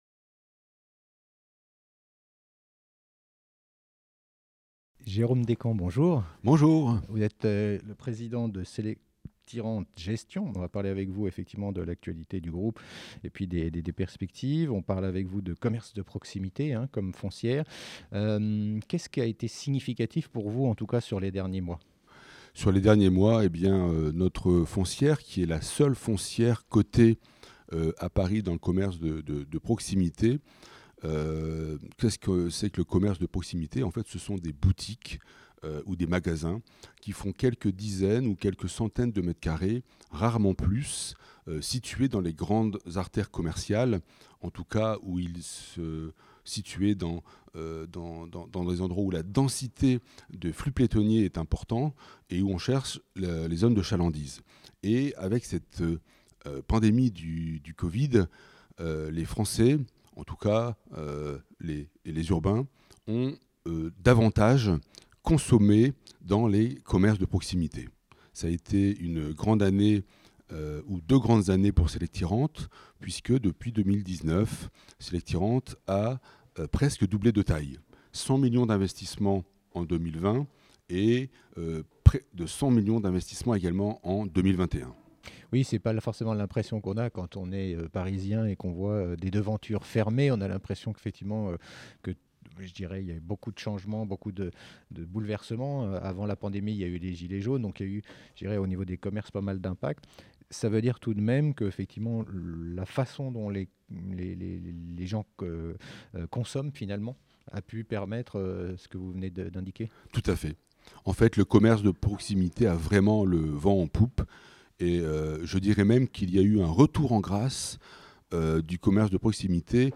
Rencontres REITS BY INVEST SECURITIES 2021 avec les foncières cotées